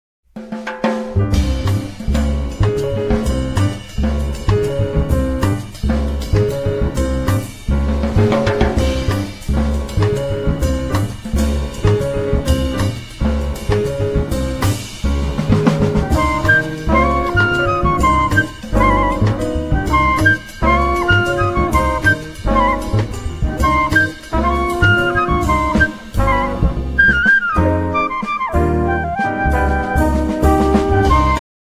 alto saxophone/flute/bass flute
trumpet/flugelhorn
piano
drums